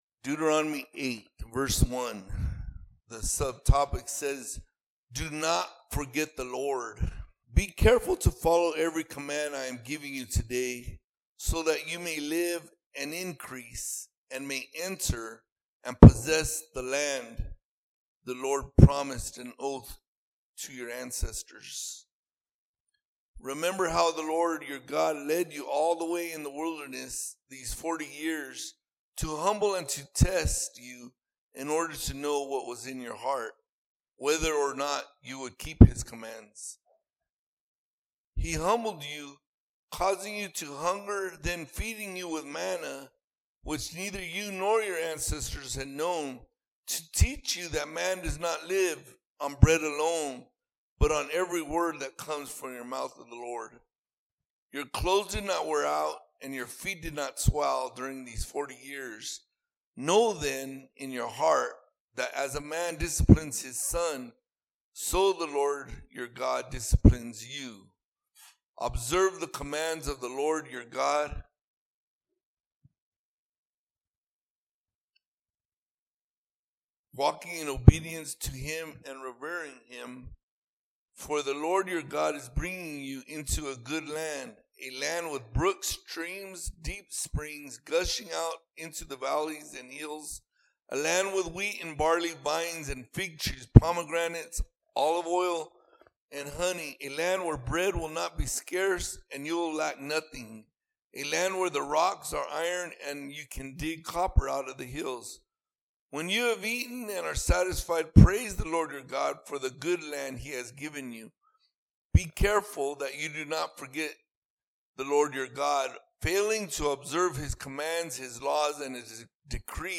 All Sermons Do Not Forget About The Lord Our God!